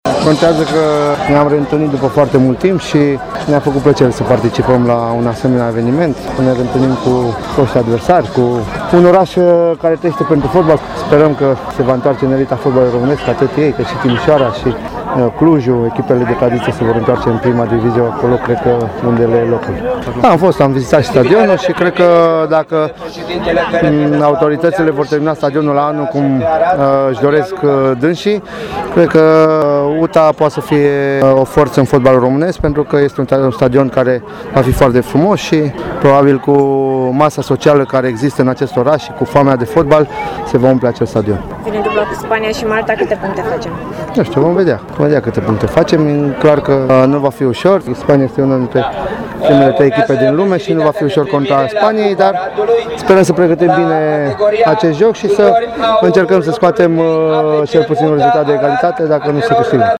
Fotbaliști de renume au onorat evenimentul și au afirmat că Aradul trebuie să revină în circuitul fotbalului mare. Selecționerul României, Cosmin Contra.